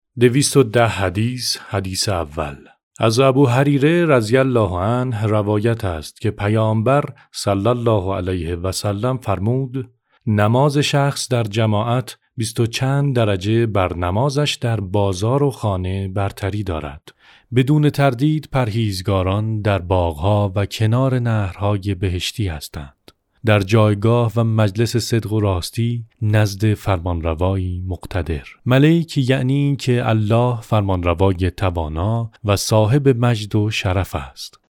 Male
Adult
Audio-Book--01